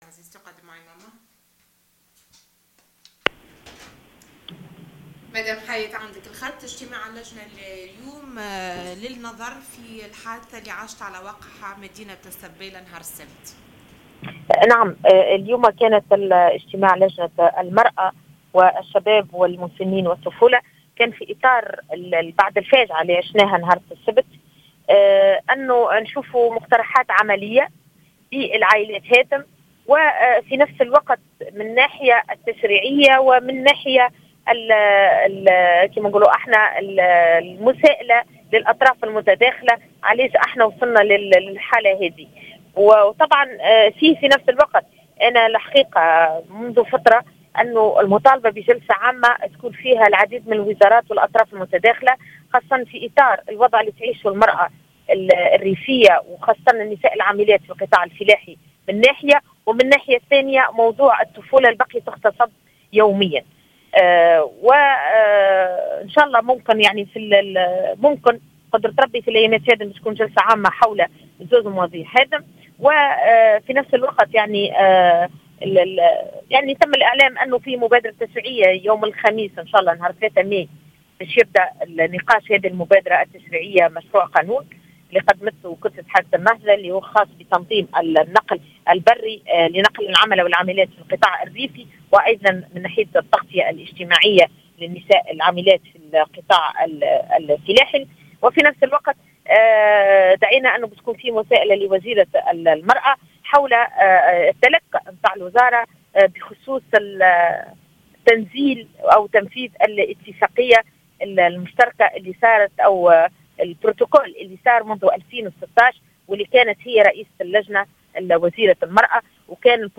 أكدت النائب بمجلس نواب الشعب ، "حياة عمري" في تصريح للجوهرة "أف أم"أن لجنة المرأة بالبرلمان والشباب والمسنين والطفولة، قررت إثر اجتماع عقدته اليوم الاثنين، دعوة وزيرة المرأة لجلسة مساءلة بخصوص البروتكول المُمضى سنة 2016 حول توفير النقل للعاملات في القطاع الفلاحي.